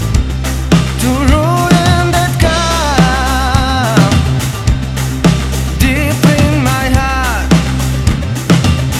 Сведен хреново и все в объемах, сложно ориентироваться.